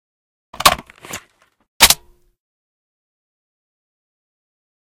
reload.ogg